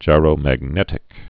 (jīrō-măg-nĕtĭk)